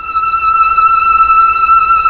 STR_TrnVlnE_6.wav